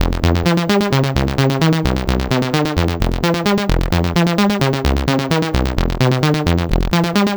Dirty Progression Fm 130.wav